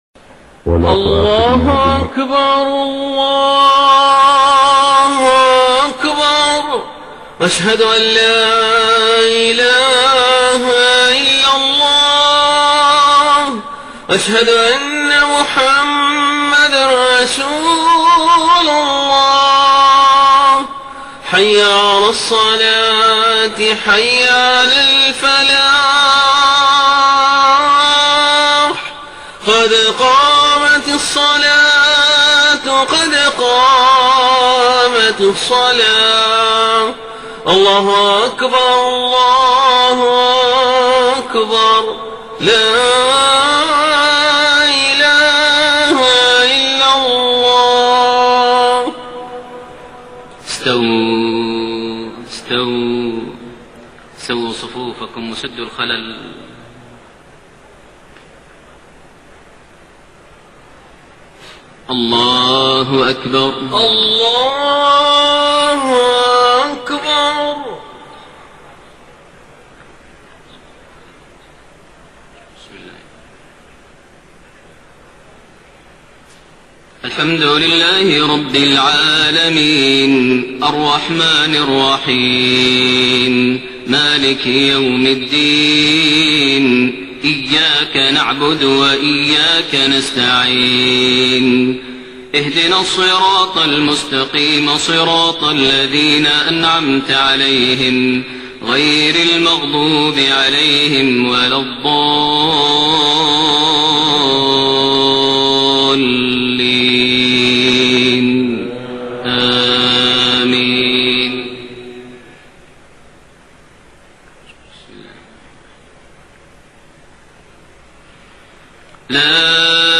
صلاة العشاء 15 ذو الحجة 1432هـ سورة القيامة > 1432 هـ > الفروض - تلاوات ماهر المعيقلي